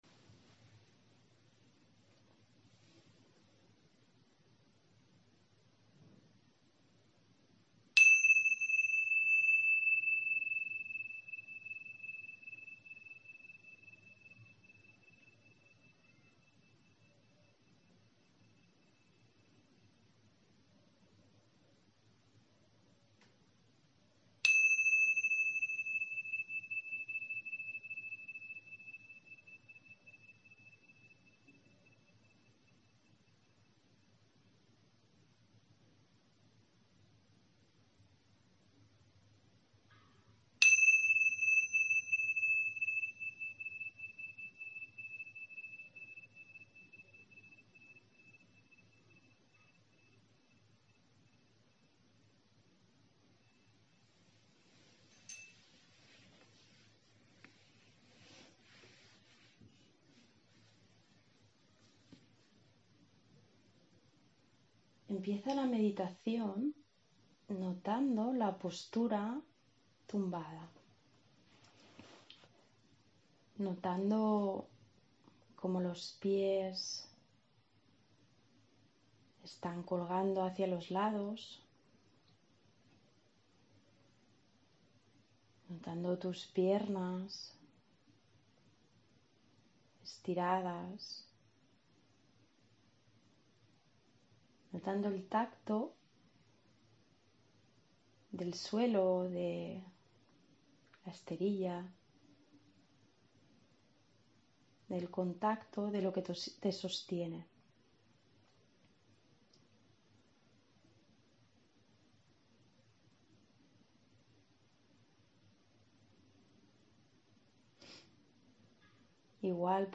– Audio con meditación guiada.